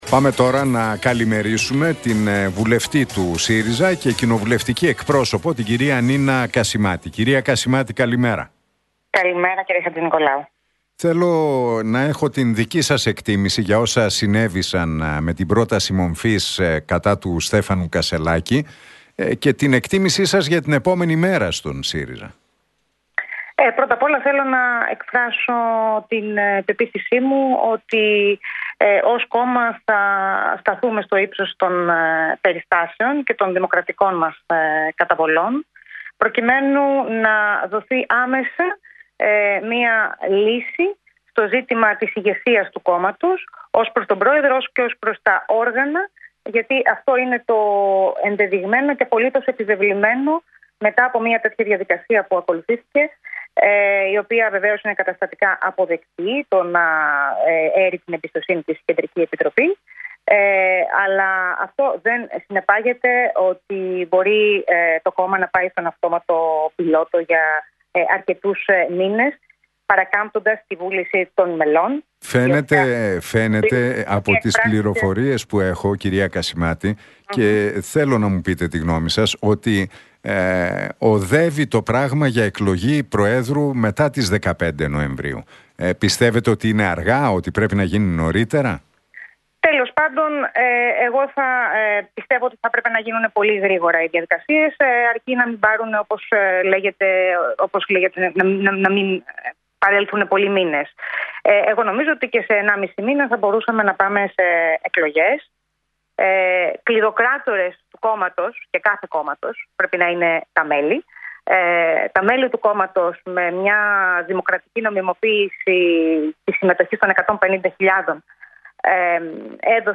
«Ως κόμμα θα σταθούμε στο ύψος των περιστάσεων και των δημοκρατικών μας καταβολών προκειμένου να δοθεί άμεσα μια λύση στο ζήτημα της ηγεσίας του κόμματος» δήλωσε η κοινοβουλευτική εκπρόσωπος του ΣΥΡΙΖΑ, Νίνα Κασιμάτη στον Realfm 97,8 και στην εκπομπή του Νίκου Χατζηνικολάου.